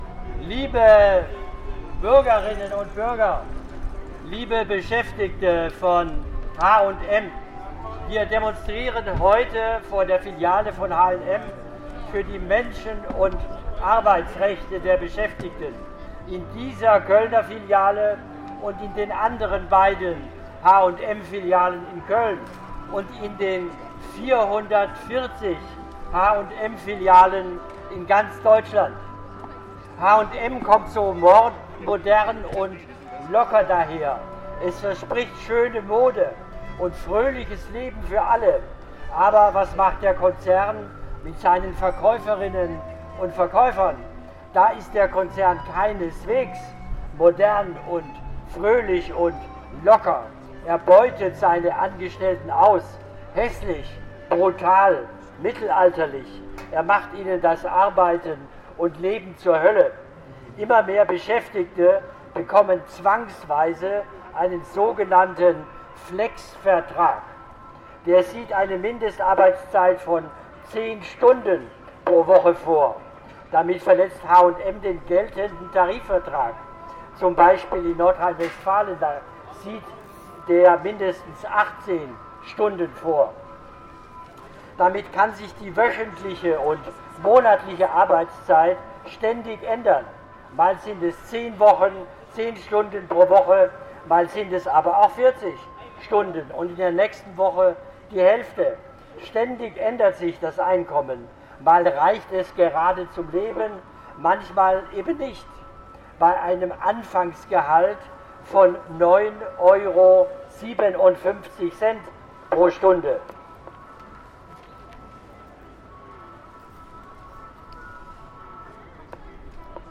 Redebeitrag